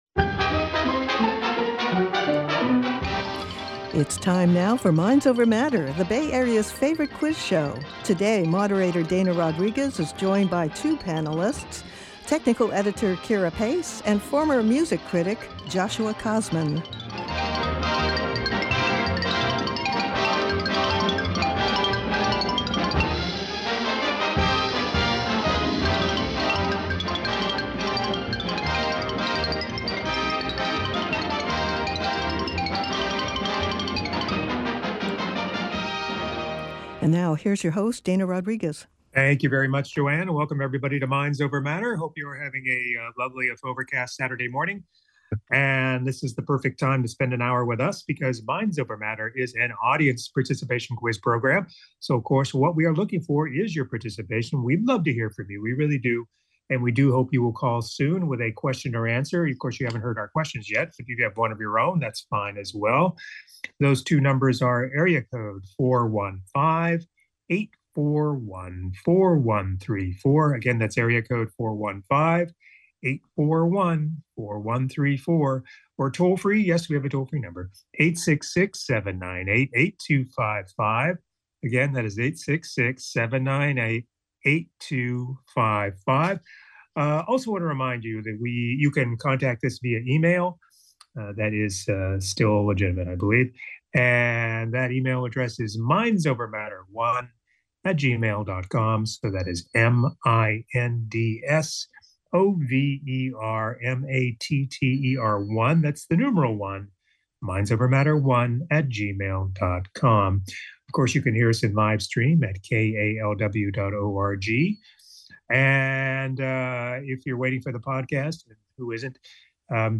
The Bay Area's favorite quiz show!